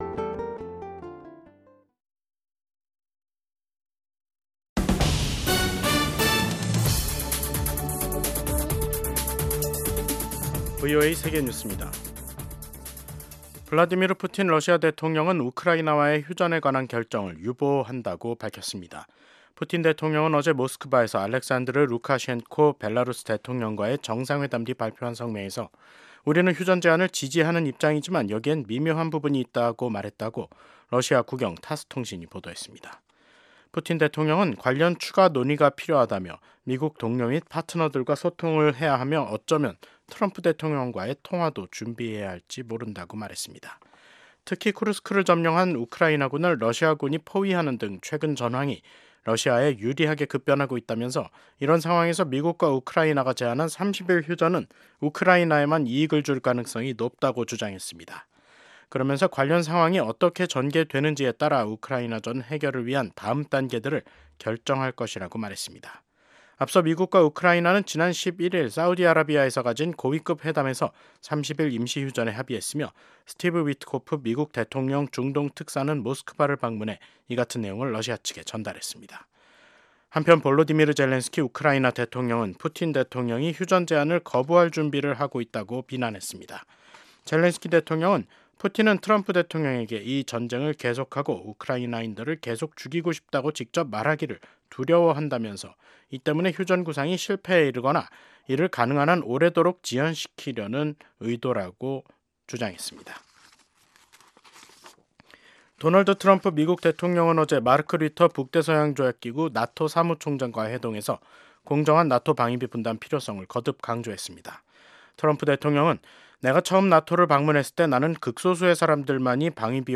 VOA 한국어 간판 뉴스 프로그램 '뉴스 투데이', 2025년 3월 14일 2부 방송입니다. 도널드 트럼프 미국 대통령이 또다시 북한을 ‘뉴클리어 파워’ 즉 ‘핵 국가’로 지칭했습니다. 일본 주재 미국 대사 지명자가 미한일 3국 협력 강화가 필수적이라며 지속적인 노력이 필요하다고 강조했습니다. 지난 10년 동안 북한 선박 8척이 중국해역 등에서 침몰한 것으로 나타났습니다.